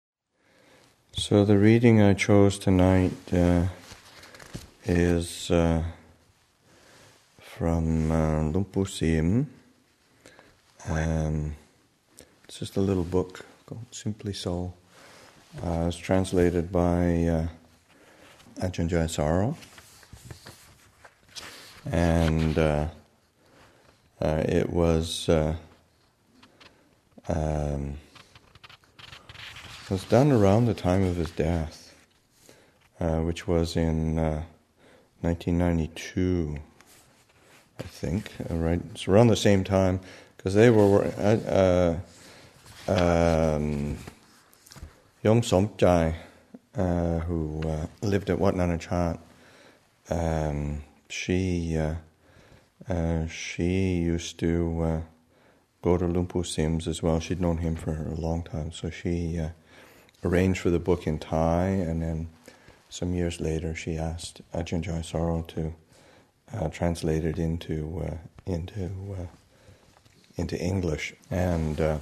Our Roots in the Thai Forest Tradition, Session 57 – Mar. 27, 2014